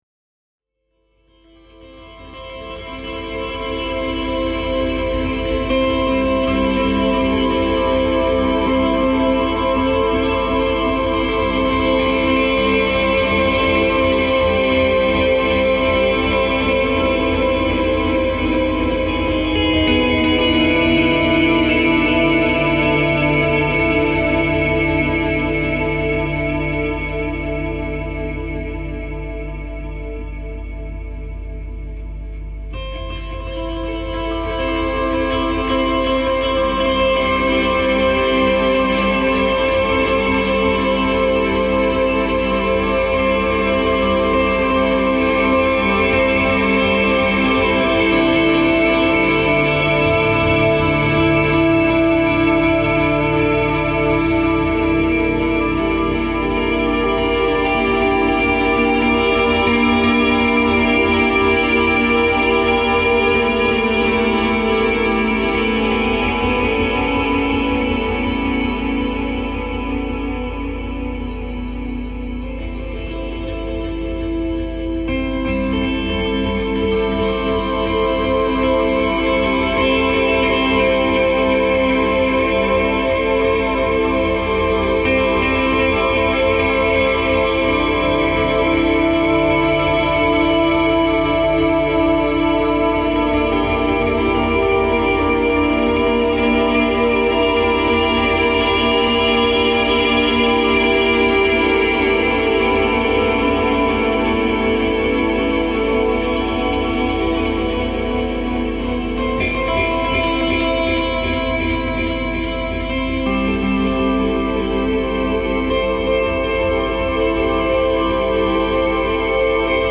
Très bonnes musiques d’ambiances, cela lance le voyage de belle manière, bravo.